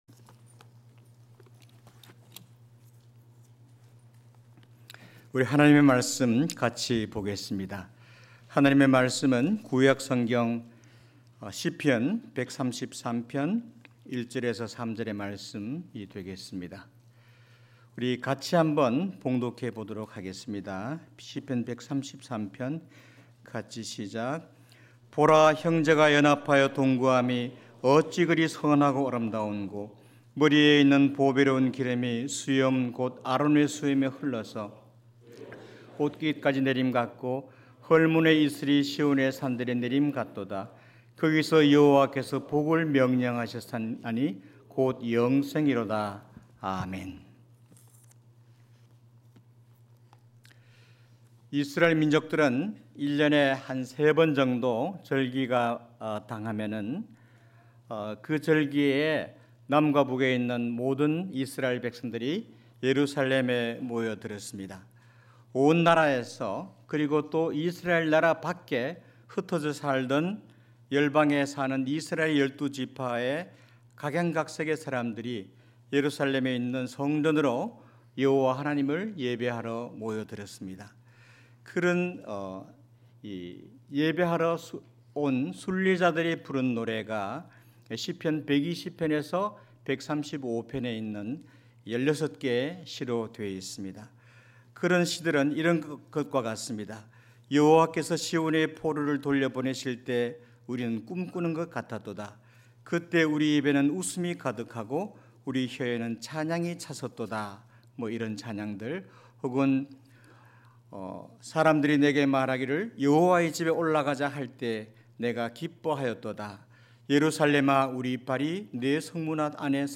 시편 133장 1-3절 관련 Tagged with 주일예배 Audio